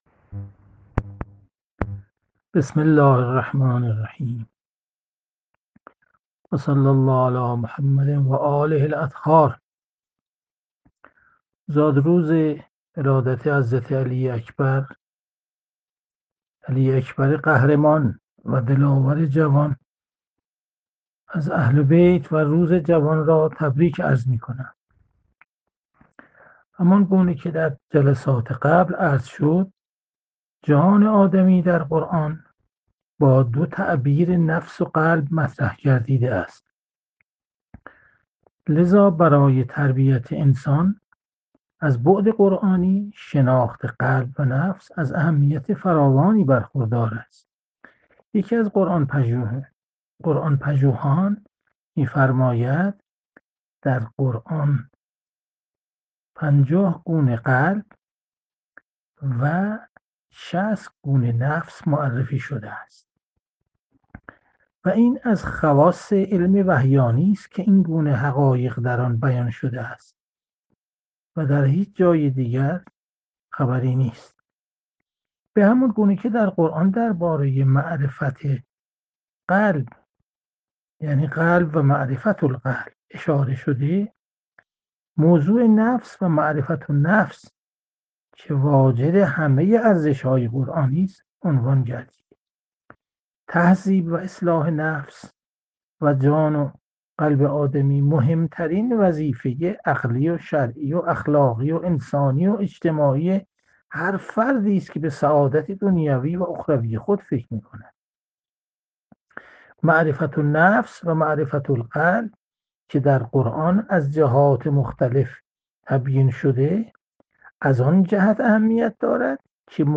جلسه مجازی هفتگی قرآنی، سوره فجر، 22 اسفند 1400
تفسیر قرآن